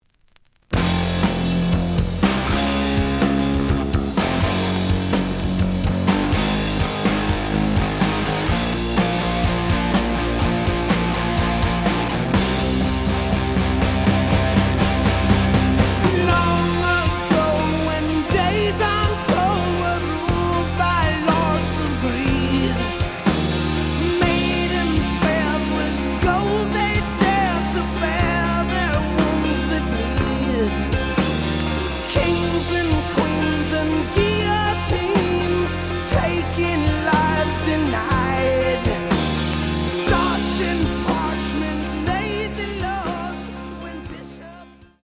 試聴は実際のレコードから録音しています。
●Genre: Rock / Pop
●Record Grading: VG~VG+ (両面のラベルにダメージ。盤に若干の歪み。プレイOK。)